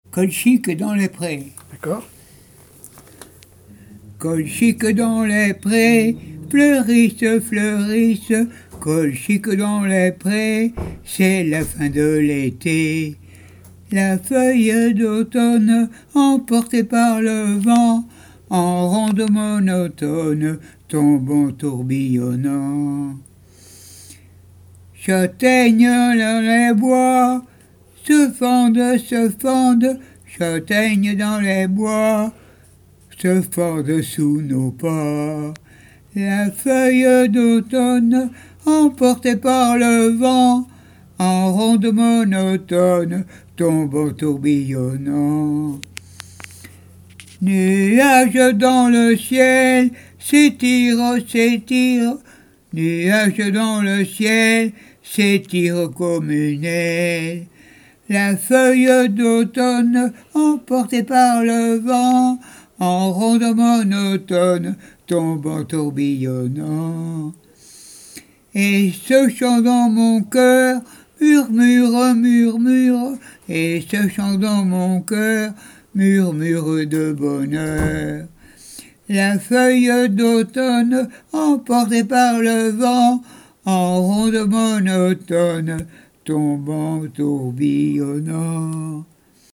Genre strophique
chansons traditionnelles et d'école
Pièce musicale inédite